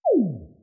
SFX_ItemDrop_01_Reverb.wav